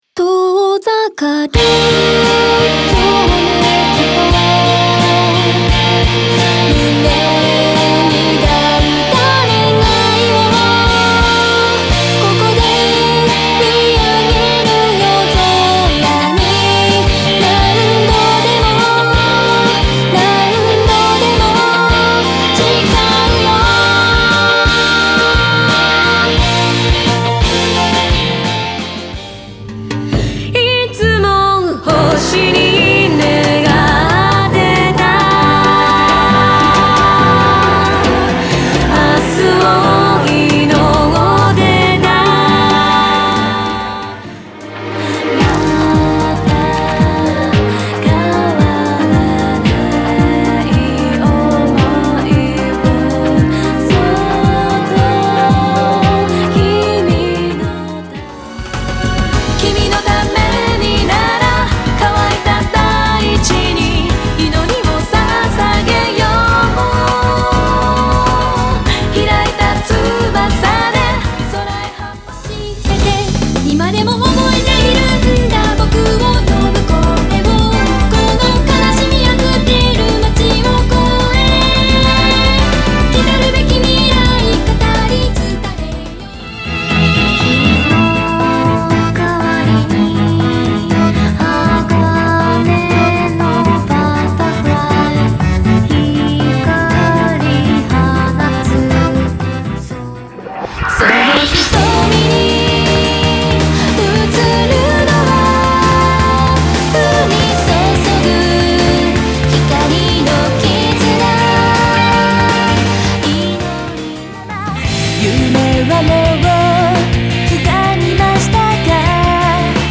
Vocal and chorus :
<全曲クロスフェード試聴はこちら>LinkIcon